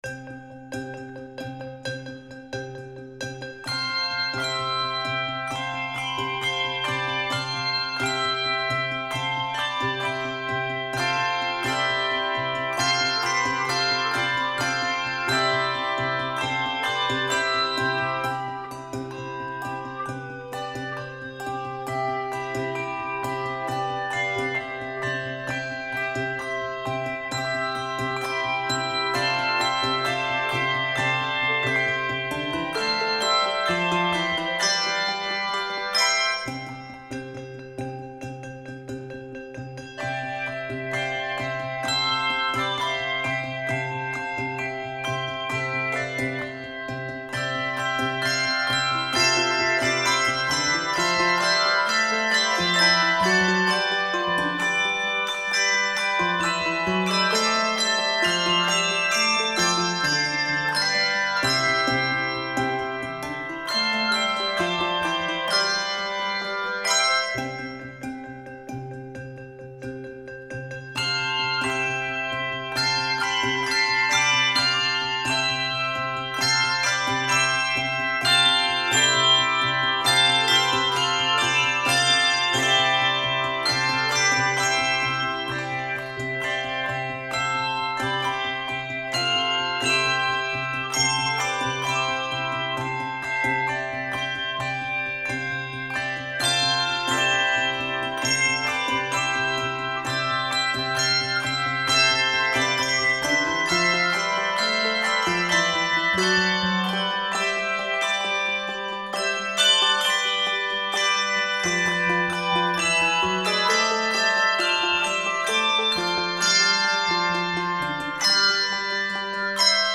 Rhythmic and exciting
Keys of C Major and Ab Major.